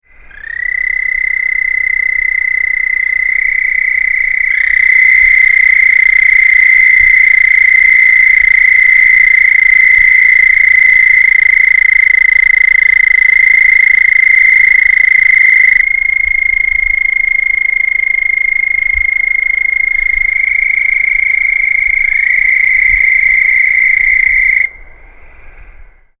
Houston Toad - Anaxyrus houstonensis
Advertisement Calls
The call of the Houston Toad is a sustained high pitched trill, lasting as long as 11 seconds.
Sound  This is a 26 second recording of the nearly deafening advertisement calls of a group of Houston Toads calling at night from the shores of a small pool in Bastrop County, Texas (shown to the right.)
bhoustonensisgroup.mp3